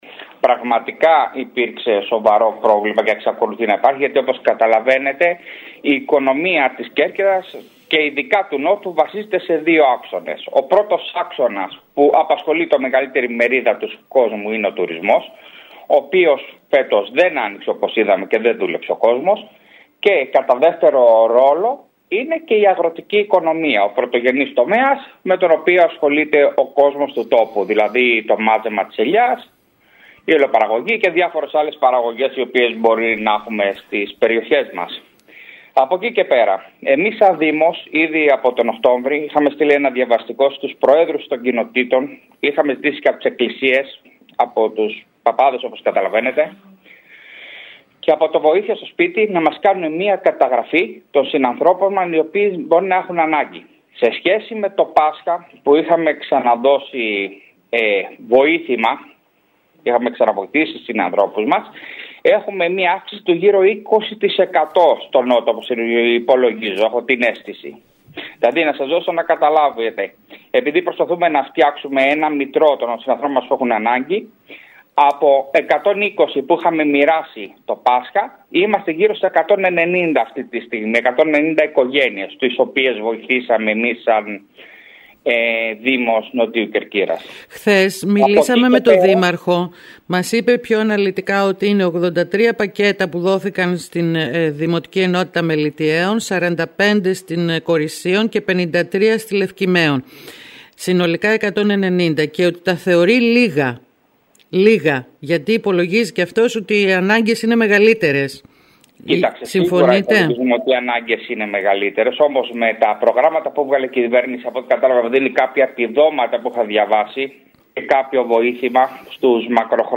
Ακούμε τον Αντιδήμαρχο κοινωνικής πολιτικής, Ανδρέα Σαγιά.